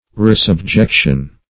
Resubjection \Re`sub*jec"tion\, n. A second subjection.